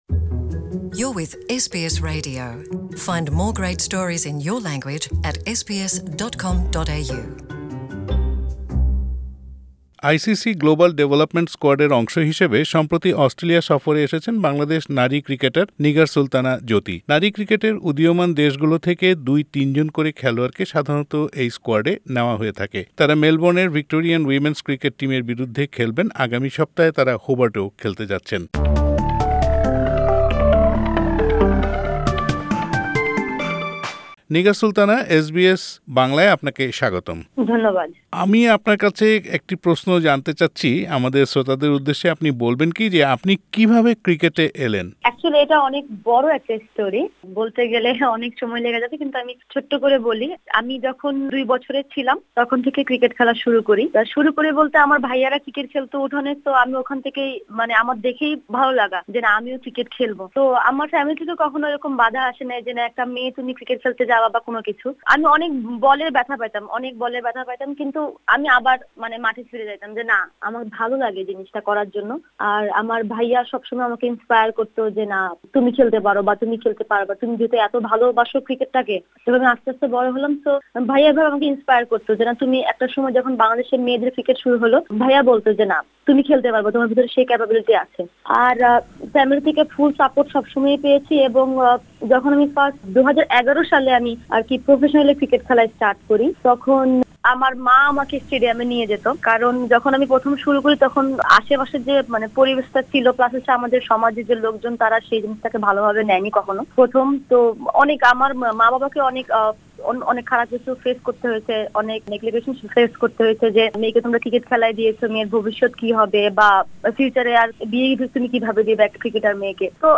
নিগার সুলতানার সাক্ষাৎকারটি বাংলায় শুনতে উপরের অডিও প্লেয়ারটিতে ক্লিক করুন।